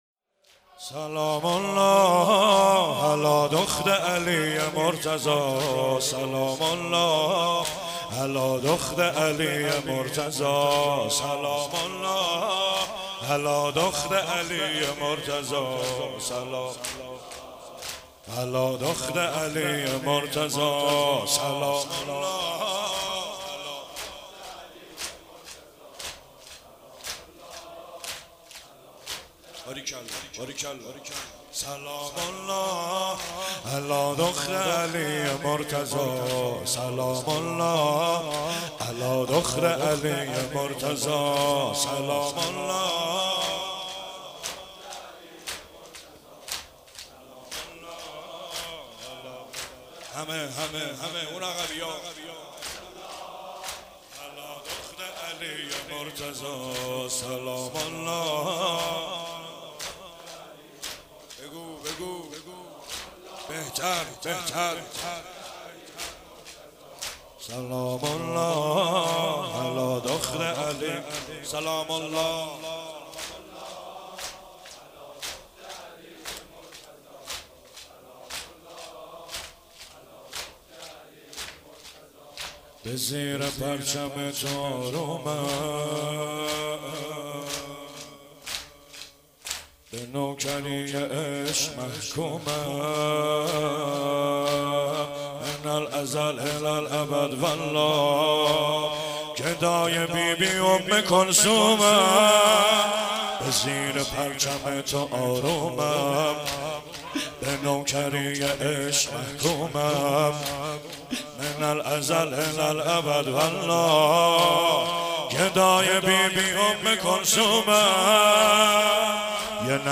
زمینه مداحی